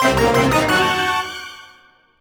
laser_panic_bad_01.wav